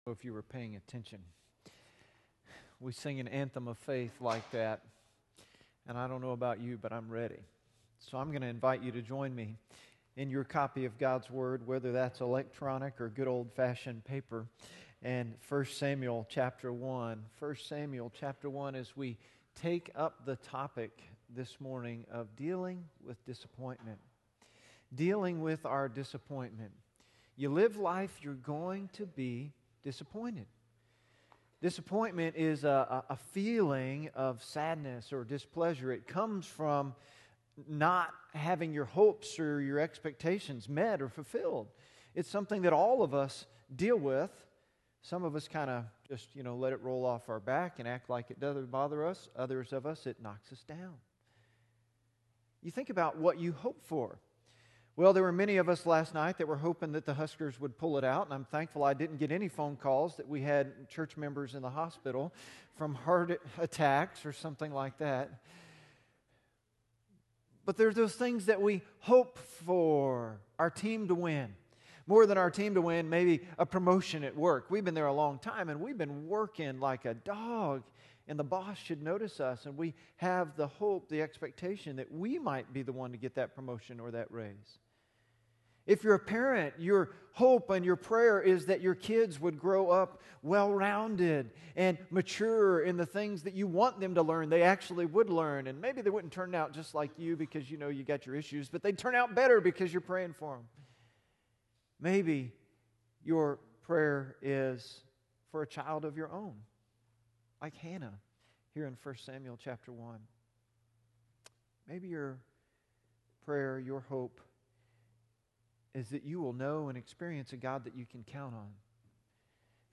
1 Samuel 1 Sermon notes on YouVersion Dealing with Disappointment